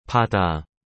“Mar” em coreano se diz 바다 (bada).
바다.mp3